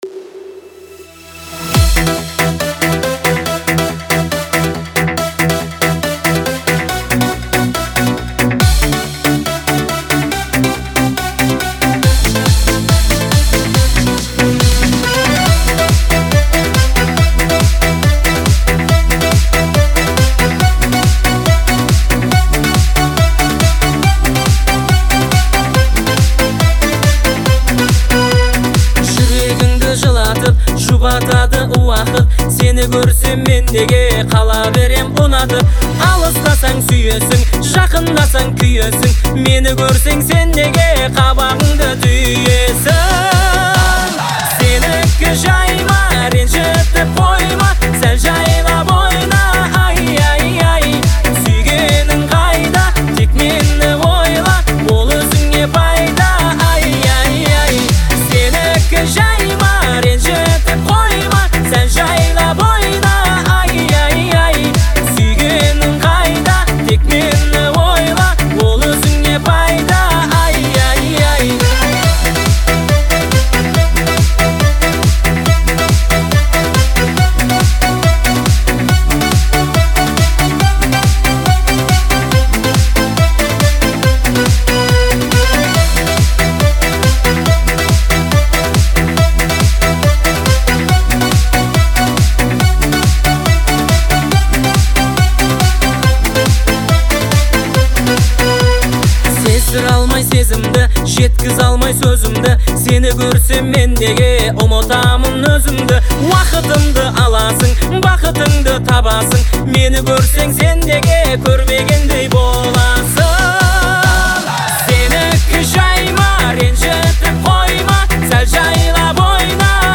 современное звучание с традиционными мотивами